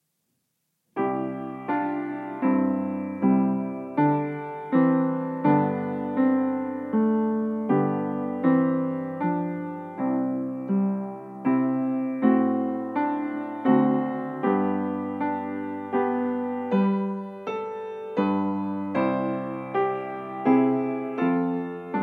Andante: 80 BMP
Nagranie dokonane na pianinie Yamaha P2, strój 440Hz
piano